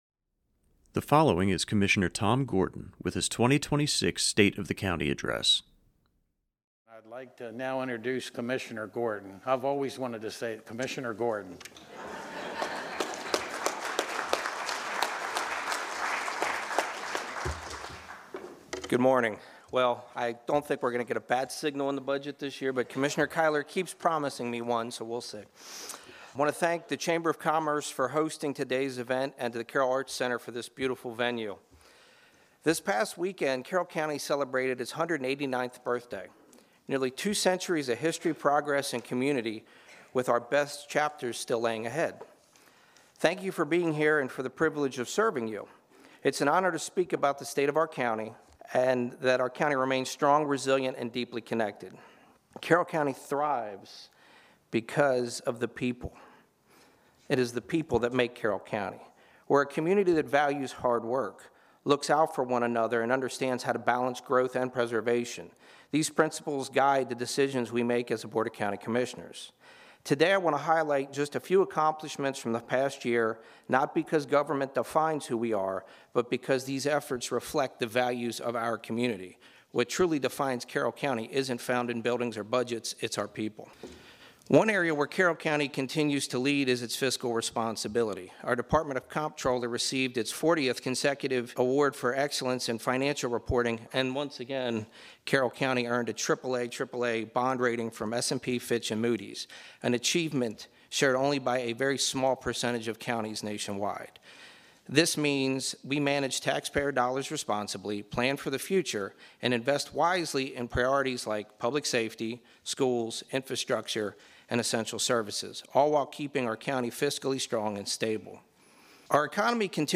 Commissioner Gordon's 2026 State Of The County Address (MP3) ~ 7214 Kb
commissioner-gordons-2026-state-of-the-county-address.mp3